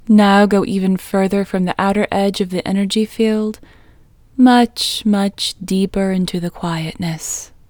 OUT Technique Female English 9